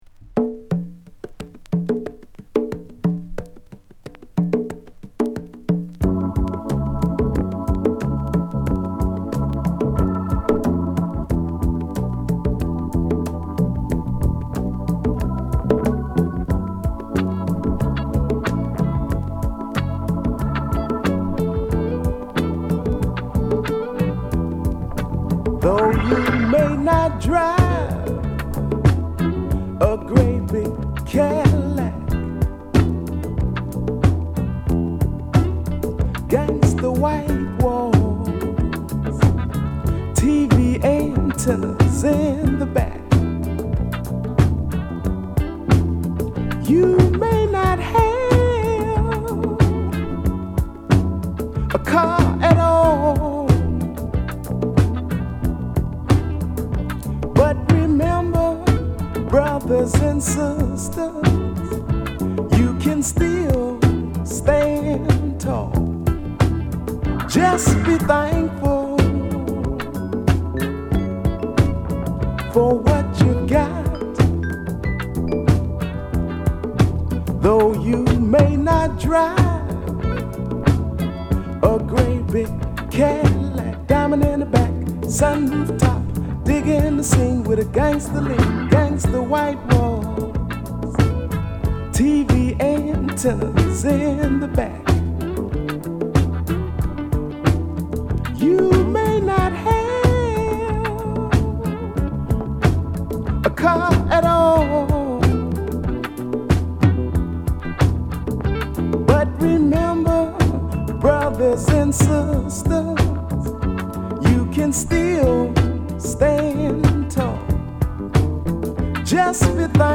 土臭くも太く緩やかなドラムに軽快なギターや、ホーン
アルバム通してホッコリとメロウな仕上がり！